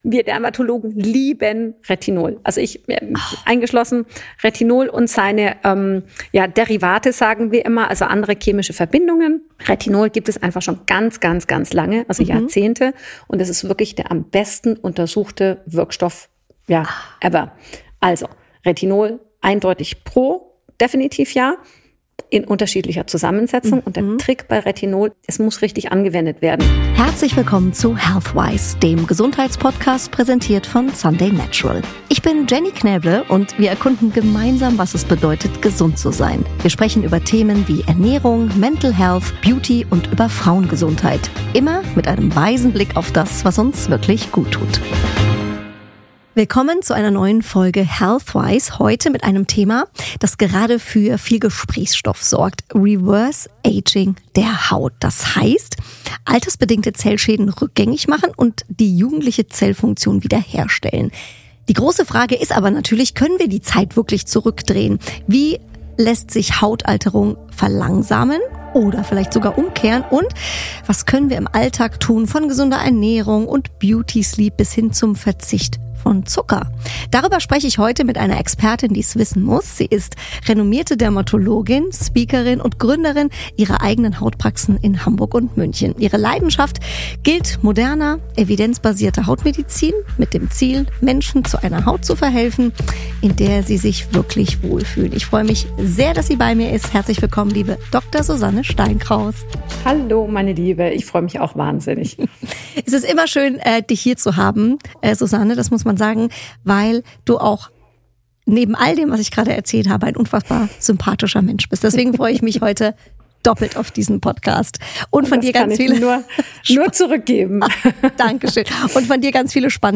Willkommen bei HEALTHWISE, dem Podcast präsentiert von Sunday Natural, der sich intensiv mit der Frage auseinandersetzt, was wahre Gesundheit in unserer modernen Gesellschaft bedeutet. Jede Episode bietet wertvolle Einblicke und inspirierende Gespräche mit Expert*innen aus verschiedenen Gesundheitsbereichen.